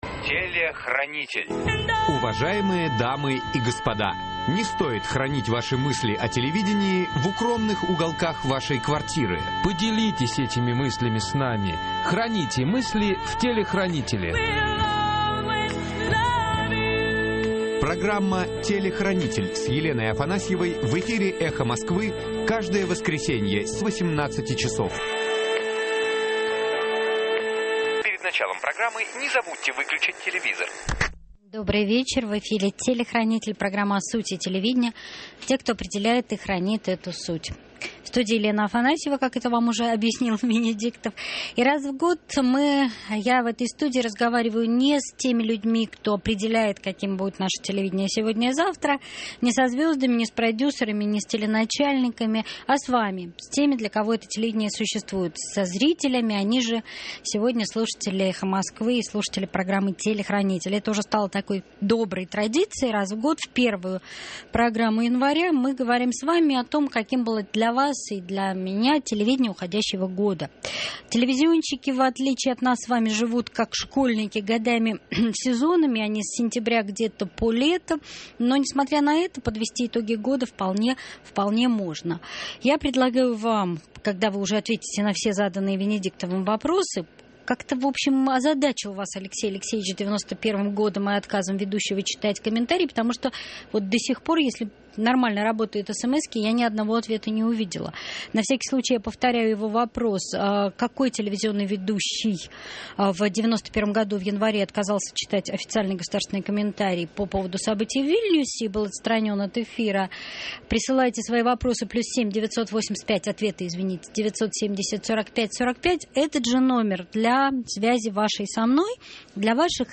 Телевидение: итоги года (традиционная новогодняя конференция со слушателями) - Телехранитель - 2009-01-04